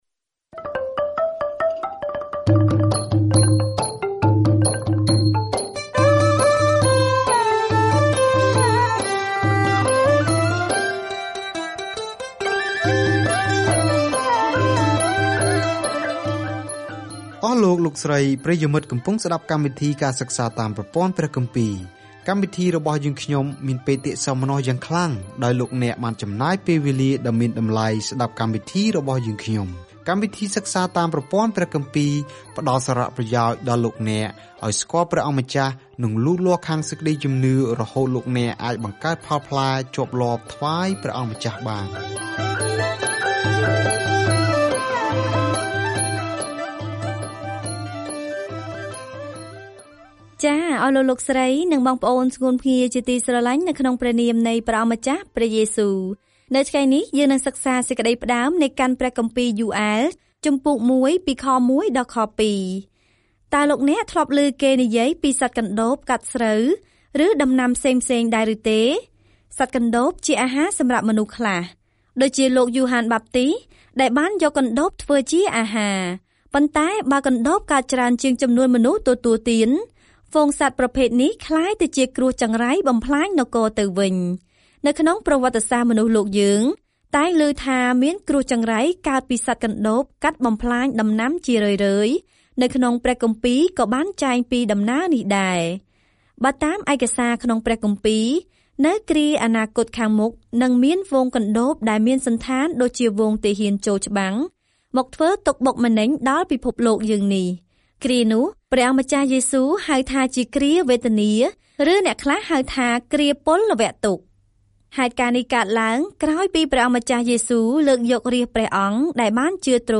ព្រះបានបញ្ជូនកណ្ដូបមកកាត់ទោសជនជាតិអ៊ីស្រាអែល ប៉ុន្តែនៅពីក្រោយការវិនិច្ឆ័យរបស់លោក គឺជាការពិពណ៌នាអំពីអនាគតនៃ«ថ្ងៃនៃព្រះអម្ចាស់» នៅពេលដែលព្រះមានបន្ទូលរបស់ទ្រង់។ ការធ្វើដំណើរប្រចាំថ្ងៃតាមរយៈ Joel នៅពេលអ្នកស្តាប់ការសិក្សាជាសំឡេង ហើយអានខគម្ពីរដែលជ្រើសរើសពីព្រះបន្ទូលរបស់ព្រះ។